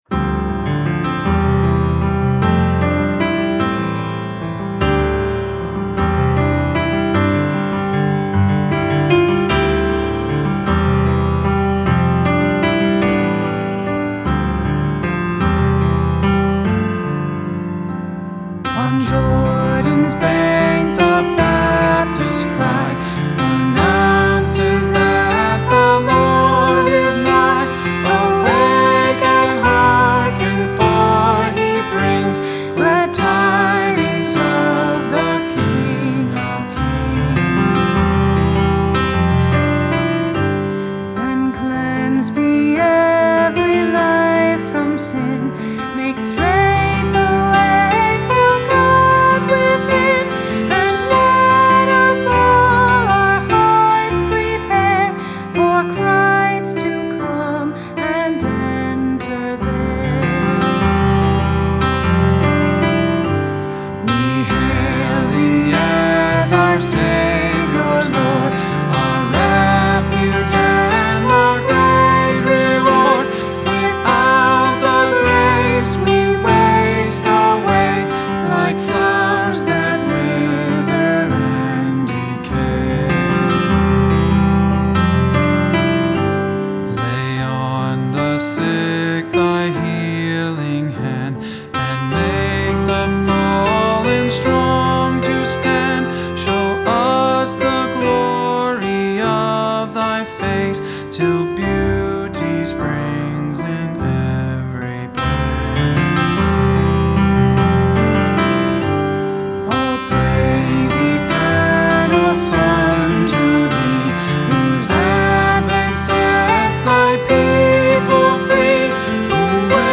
Hymn
The Hymnal Project